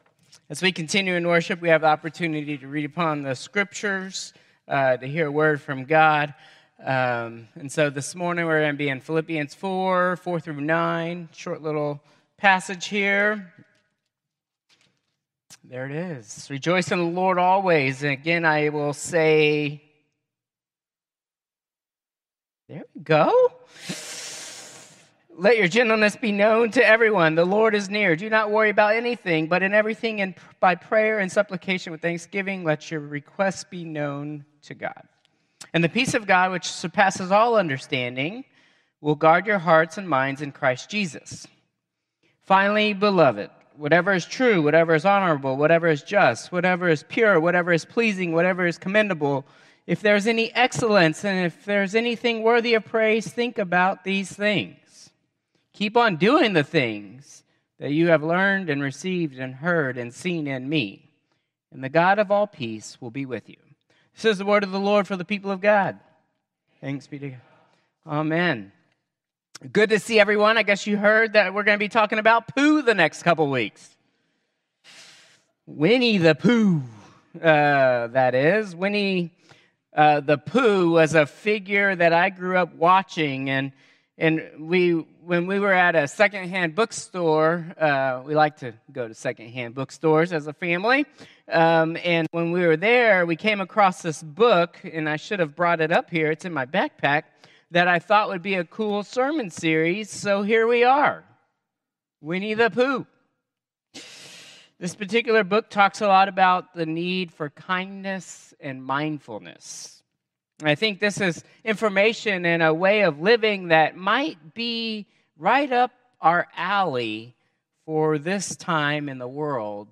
Contemporary Service 11/17/2024